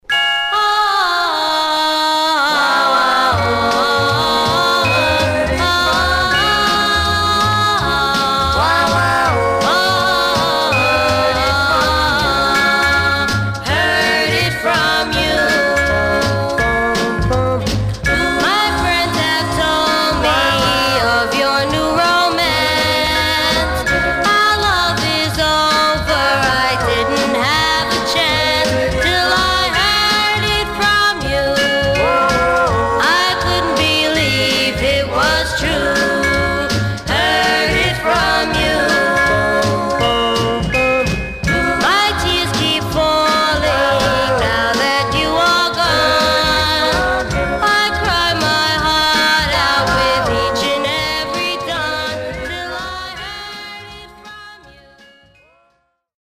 Condition Surface noise/wear Stereo/mono Mono